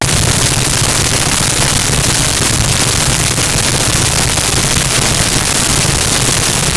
Machine Low Hum
Machine Low Hum.wav